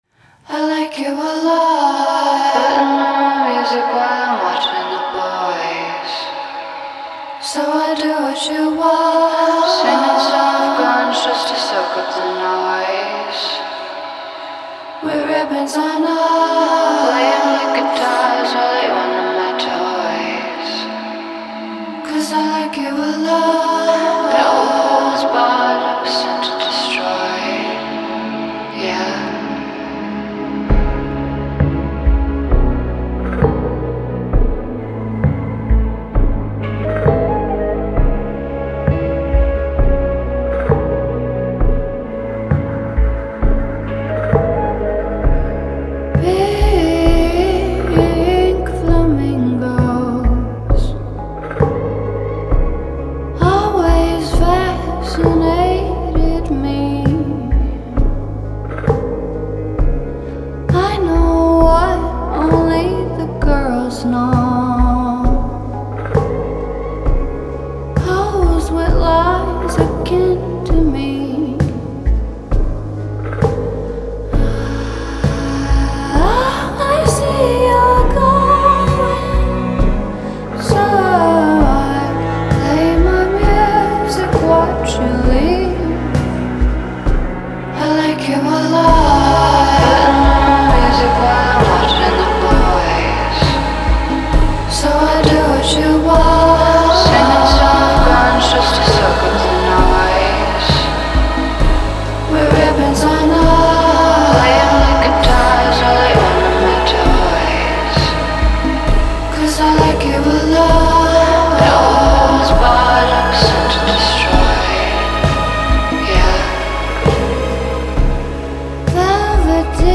trademark retro-60s Hollywood vamp vibe